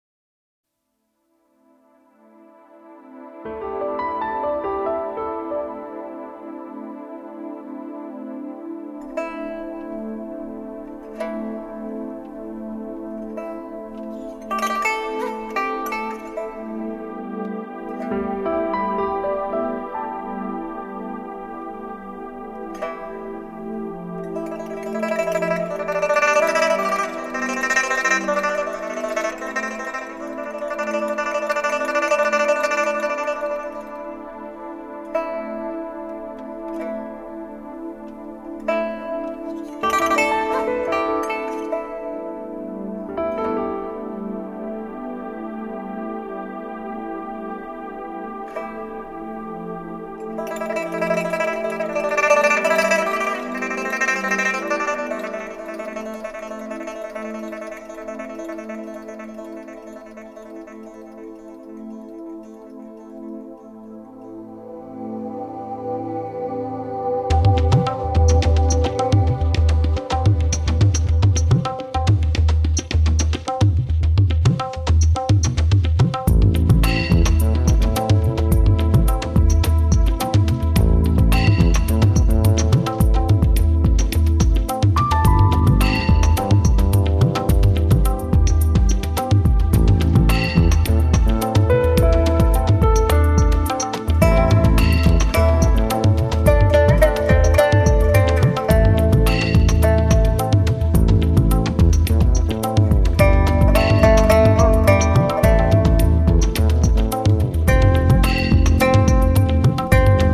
★ 融會貫通東西方樂器、傳統與現代的跨時代完美樂章！
★ 輕柔紓緩的美麗樂音，兼具令人震撼感動的發燒音效！